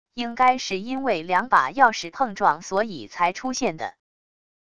应该是因为两把钥匙碰撞所以才出现的wav音频